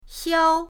xiao1.mp3